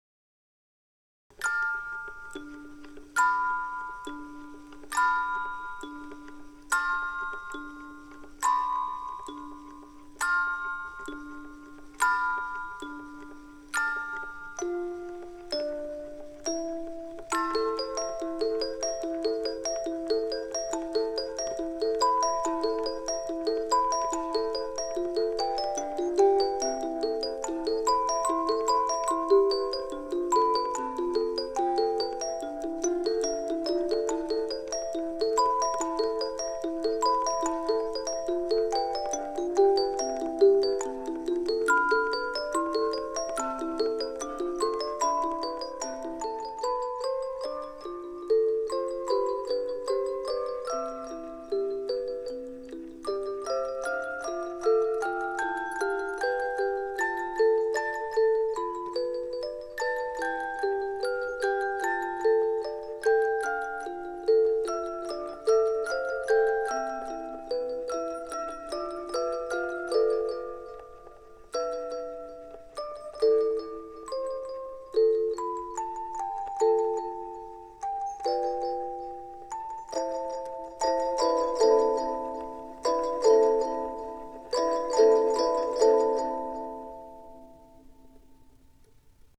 ビデオとパフォーマンスのサウンドトラックとしてつくったものです。
オルゴール